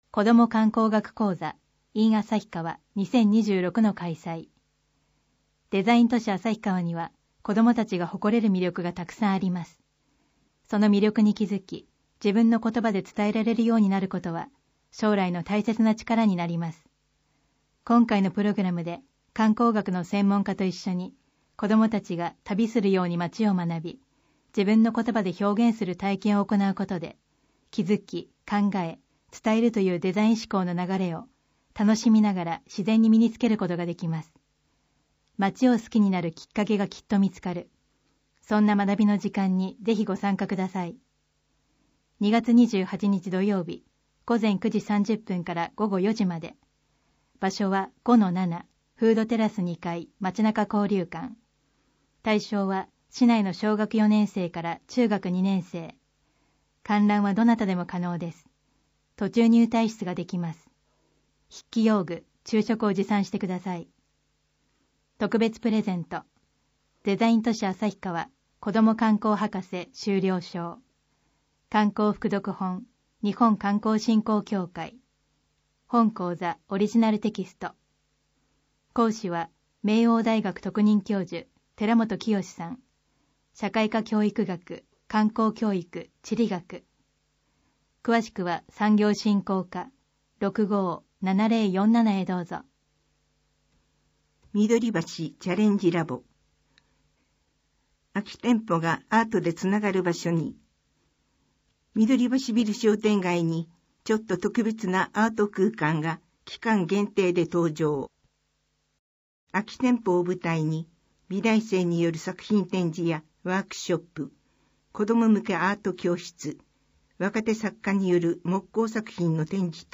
広報誌の内容を要約し、音訳した声の広報「あさひばし」を、デイジー図書版で毎月発行しています。